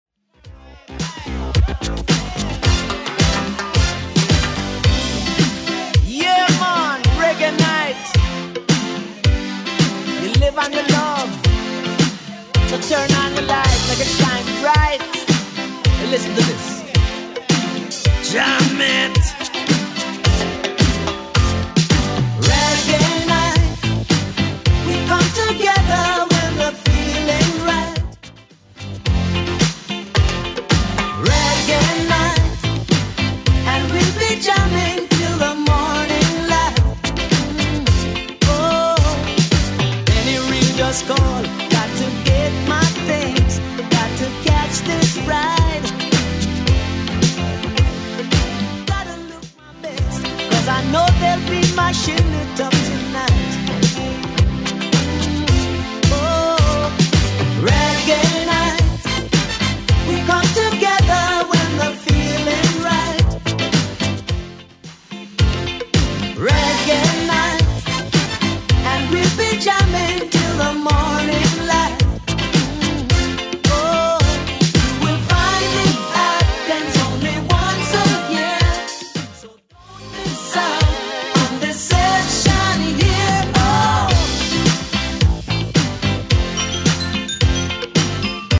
BPM: 109 Time